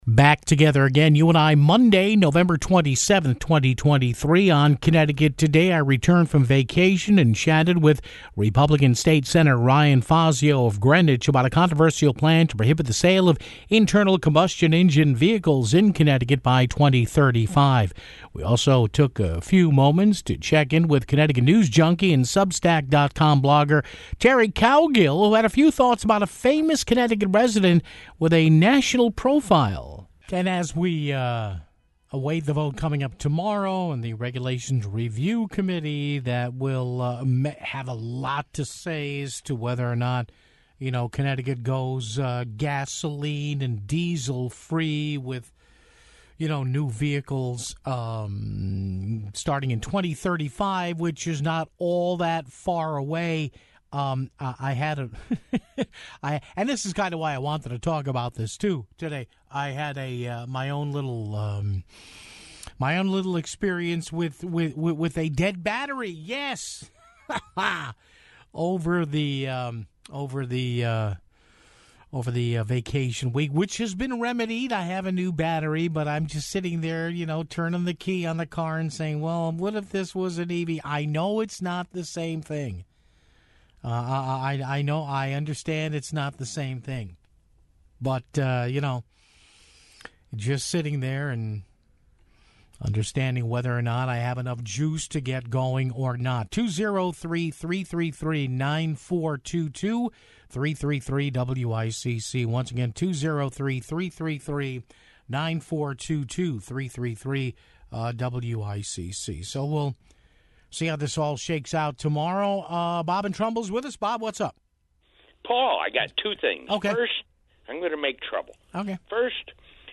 chatted with Greenwich GOP State Sen. Ryan Fazio about a controversial plan to prohibit the sale of internal combustion engine vehicles in Connecticut by 2035 (00:30).